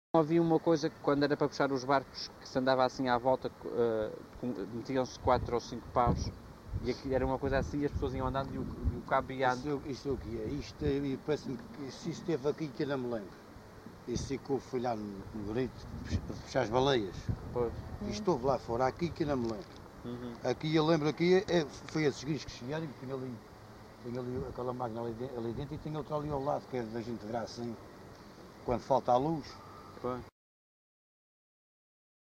LocalidadeSão Mateus da Calheta (Angra do Heroísmo, Angra do Heroísmo)